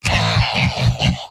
Giant Robot lines from MvM.
Heavy_mvm_m_laughshort03.mp3